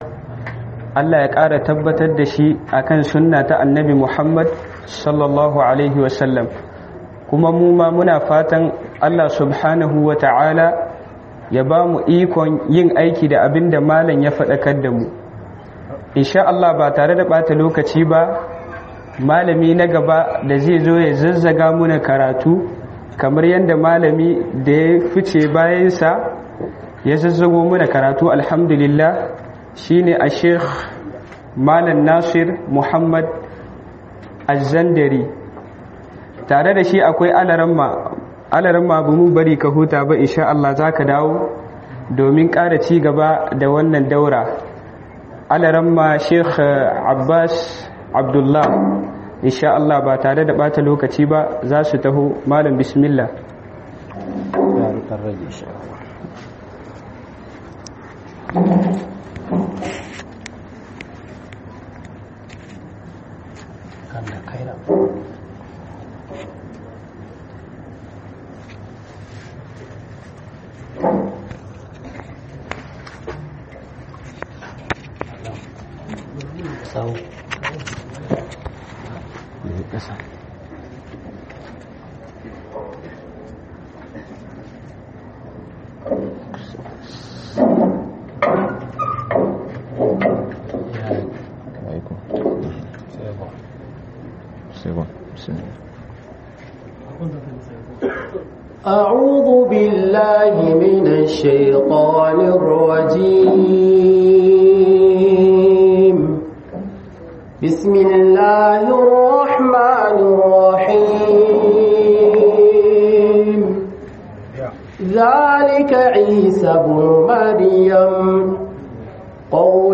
Tauhidi da falalar sa - Muhadara